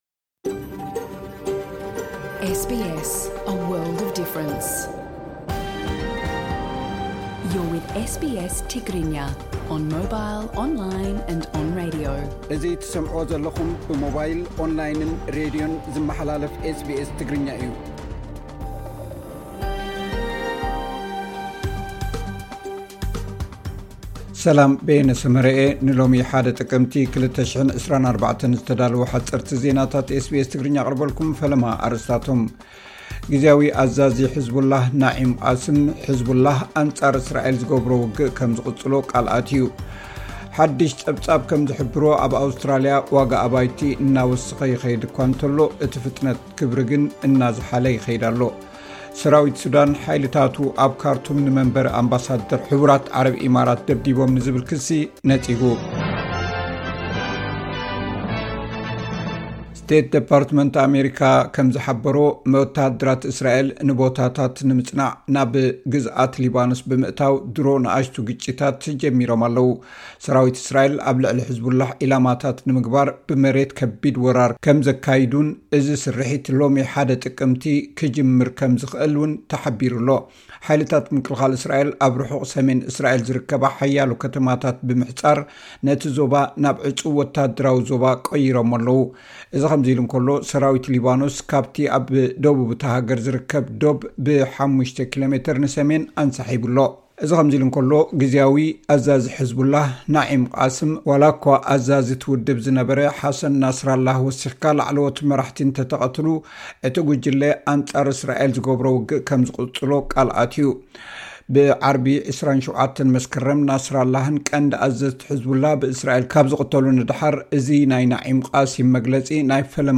ሓጸርቲ ዜናታት ኤስ ቢ ኤስ ትግርኛ (01 ጥቅምቲ 2024)